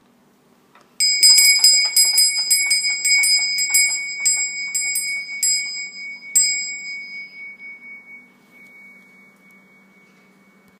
Brass Winchester Bell Pull
The entire pull is mechanically operated.